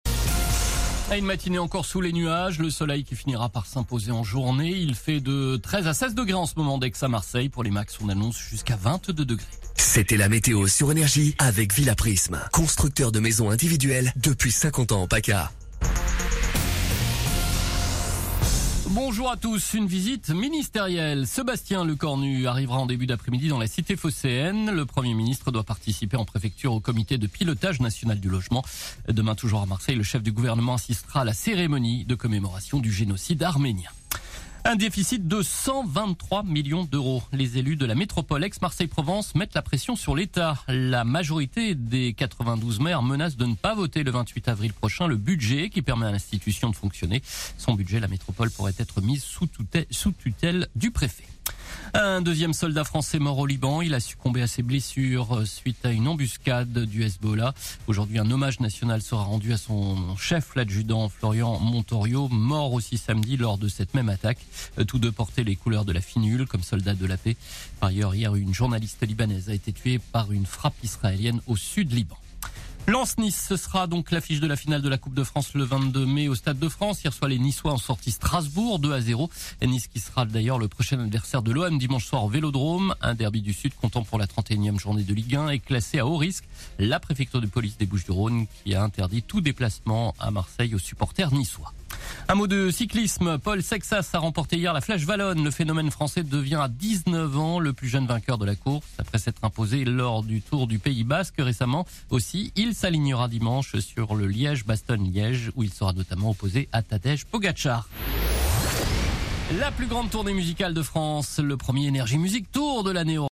Réécoutez vos INFOS, METEO et TRAFIC de NRJ MARSEILLE du jeudi 23 avril 2026 à 09h00